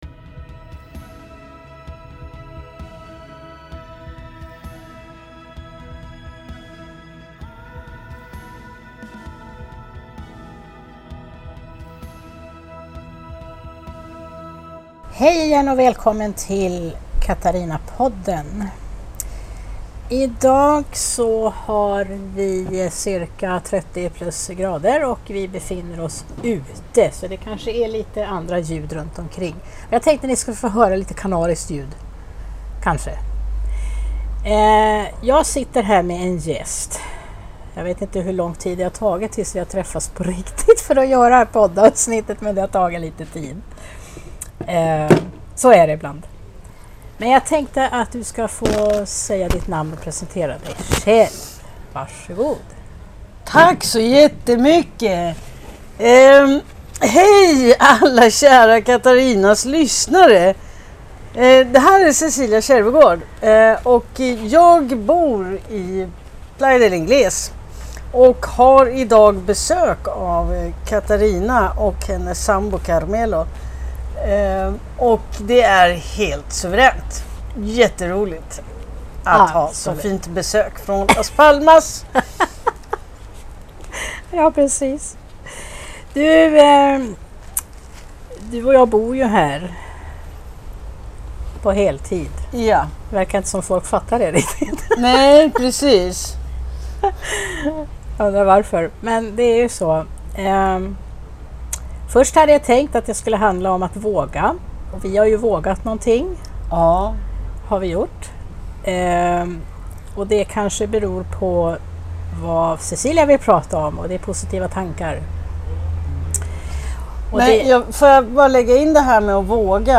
Vi sitter ute i Playa de Ingles på Gran Canaria.